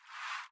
AmmoOpen.wav